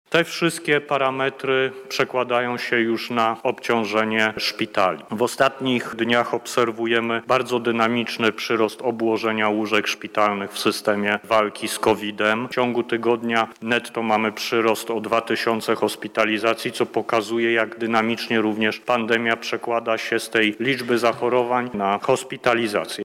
• mówi minister zdrowia Adam Niedzielski.